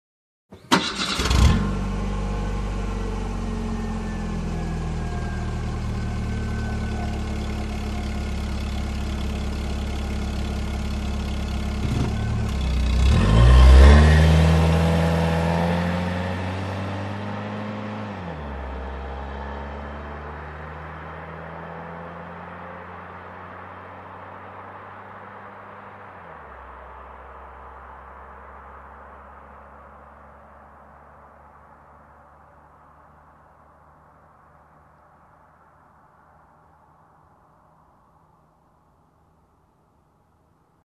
Звуки заводящейся машины - скачать и слушать онлайн бесплатно в mp3